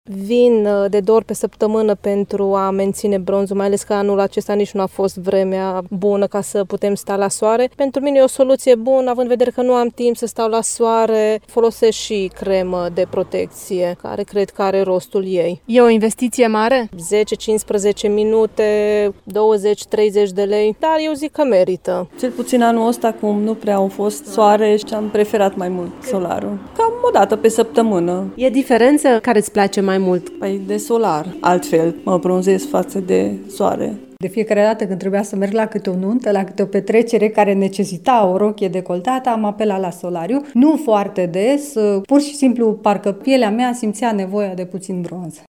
Clienții spun că trebuie să se bronzeze, fie pentru a arăta bine la evenimente, fie pentru a ascunde imperfecțiunile pielii. Alții nu-și pot imagina vara fără bronz: